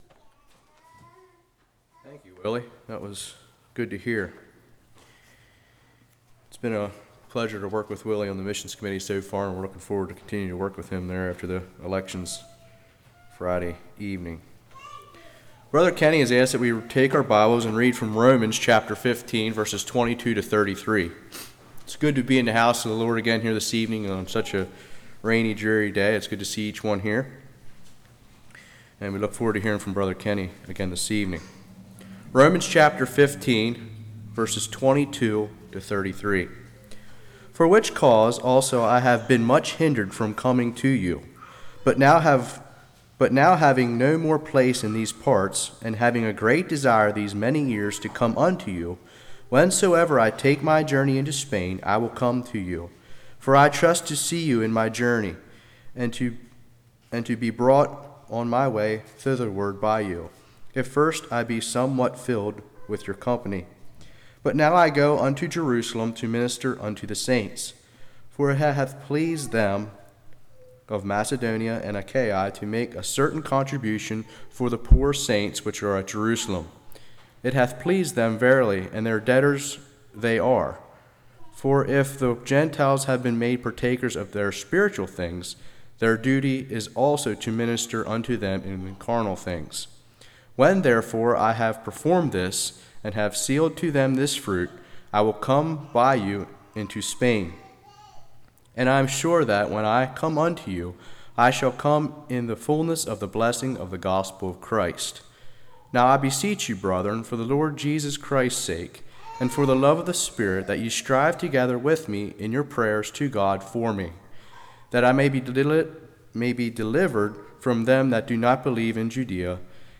Service Type: Evening